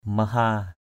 /mə-ha:/